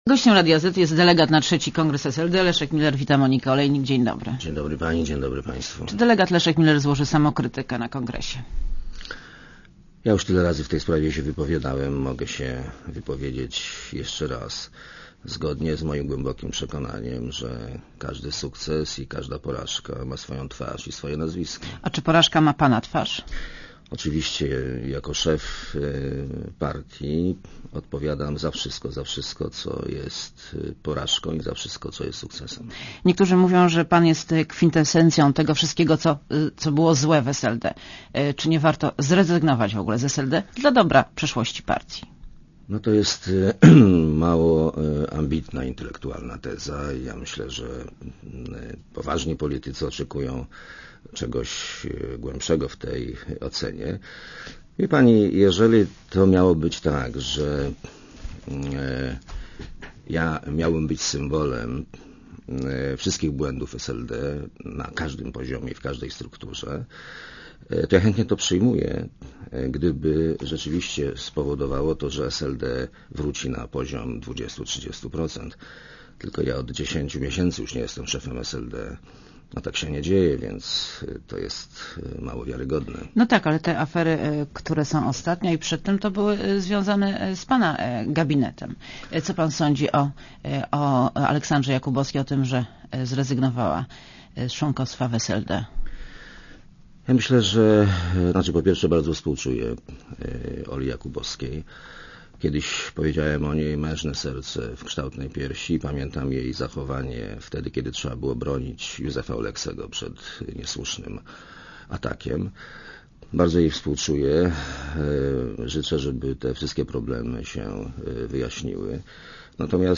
Leszek Miller w Radiu ZET (RadioZet)